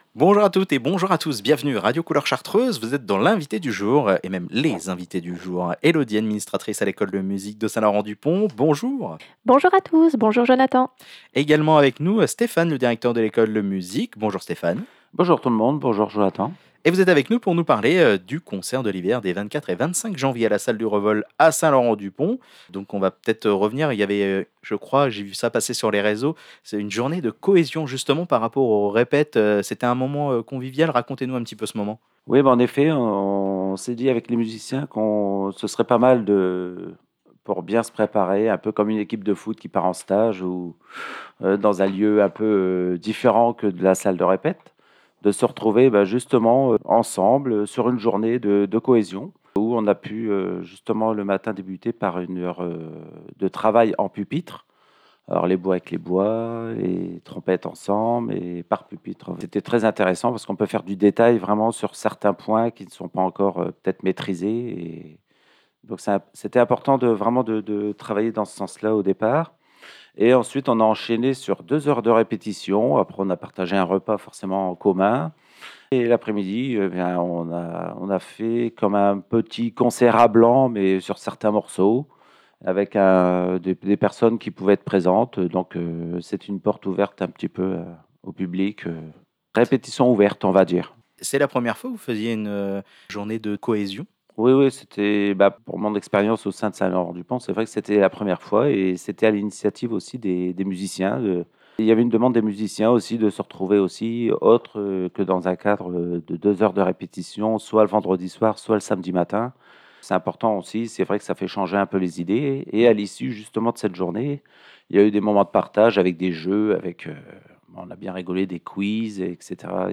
ITW CONCERT ECOLE MUSIQUE SLDP DIF MERC 21 JANV_PAD.wav